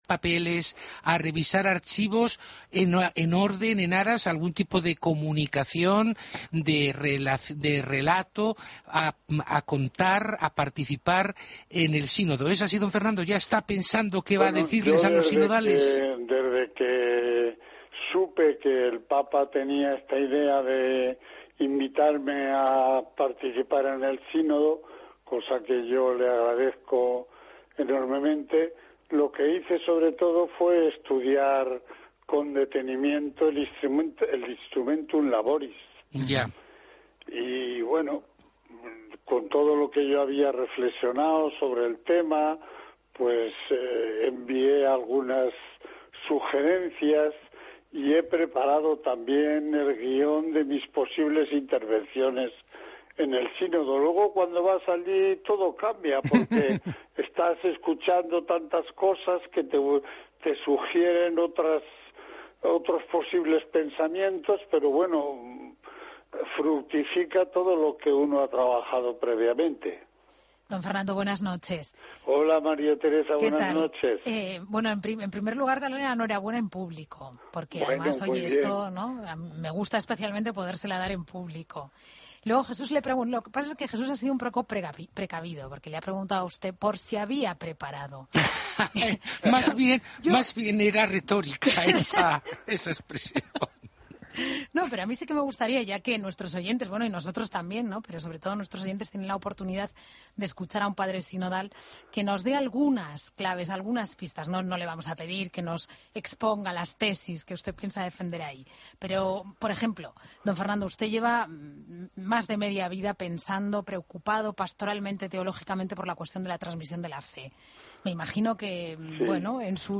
AUDIO: Entrevista a Fernando Sebastián en La Linterna de la Iglesia